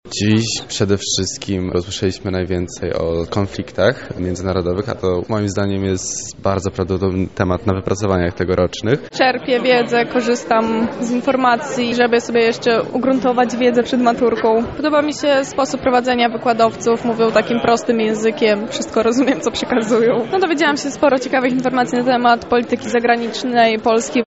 Była tam również nasza reporterka.
Nocne-Korki-z-WOSu-relacja-1.mp3